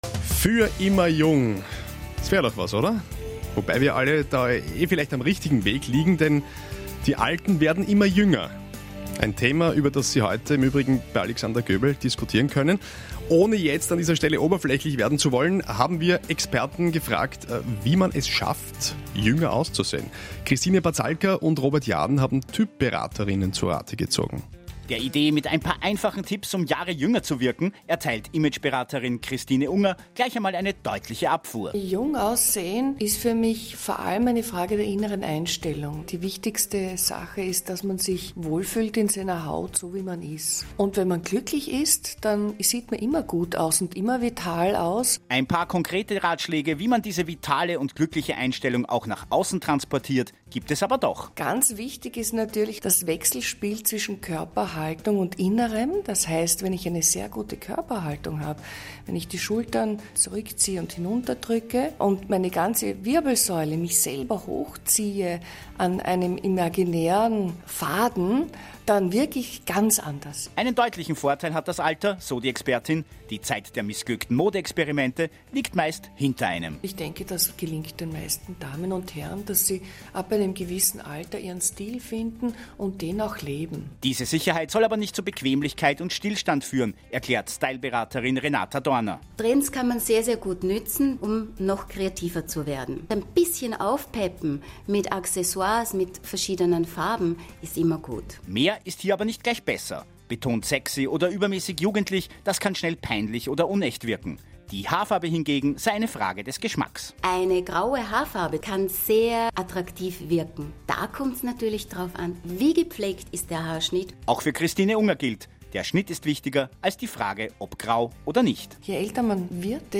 Interview für Radio Wien vom April 2015